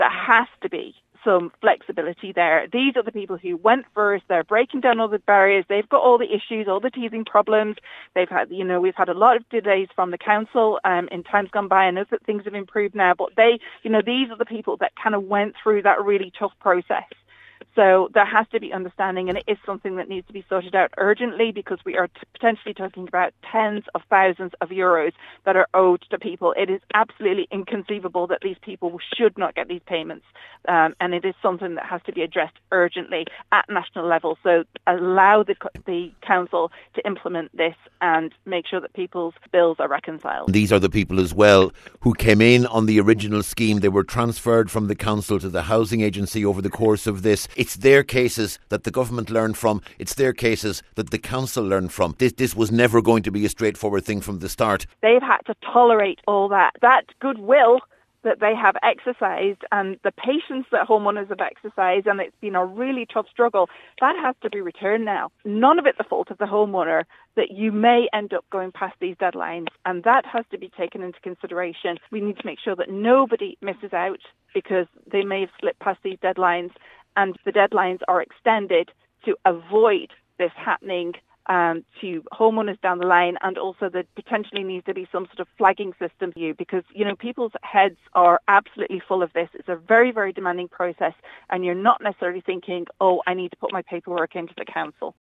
a meeting of Donegal County Council’s Defective Blocks Committee